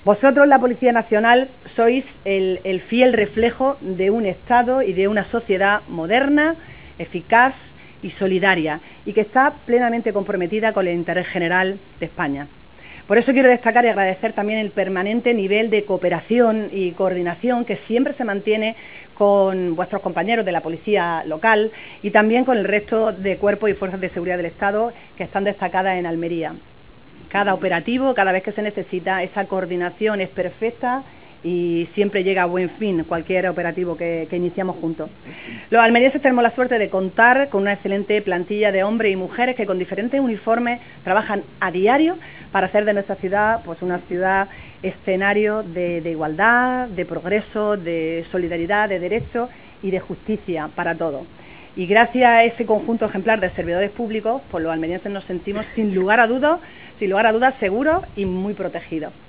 TOTAL-ALCALDESA-INAUGURACION-PARQUE-HOMENAJE-POLICIA-NACIONAL.wav